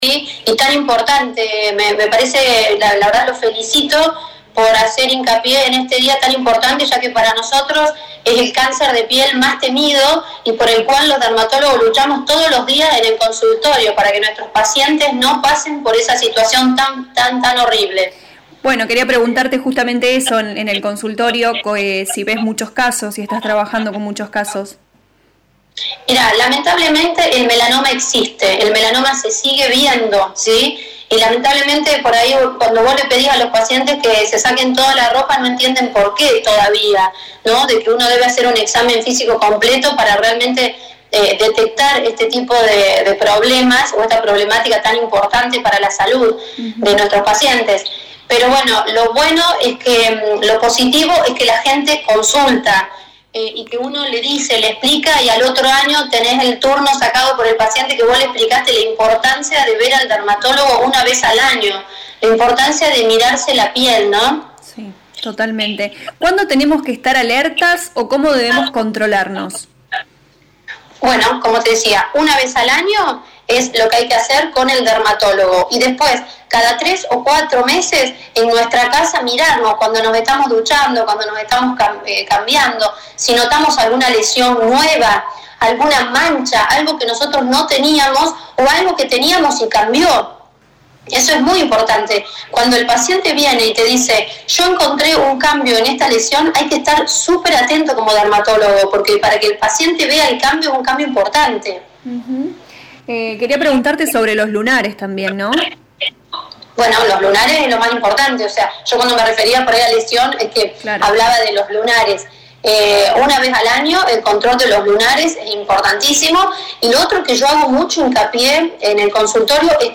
En el marco del Día Mundial contra el Melanoma, LA RADIO 102.9 FM dialogó con la médica dermatóloga